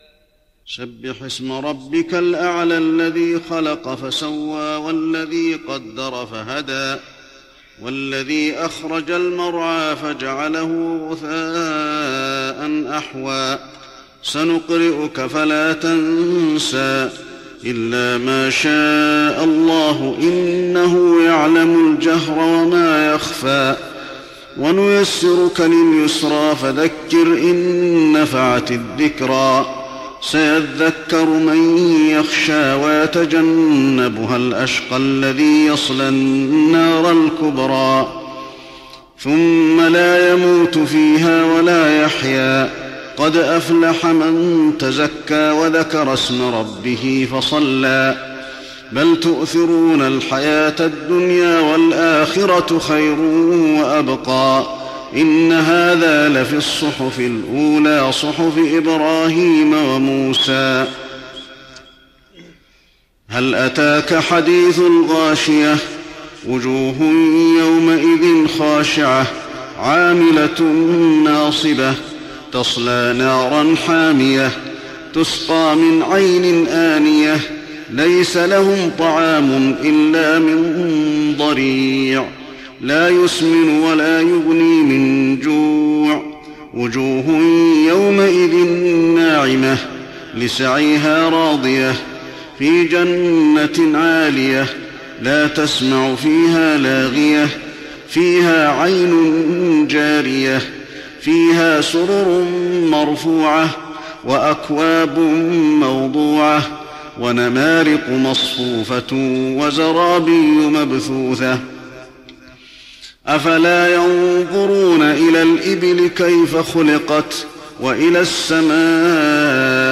تراويح رمضان 1415هـ من سورة الأعلى الى سورة الناس Taraweeh Ramadan 1415H from Surah Al-A'laa to Surah An-Naas > تراويح الحرم النبوي عام 1415 🕌 > التراويح - تلاوات الحرمين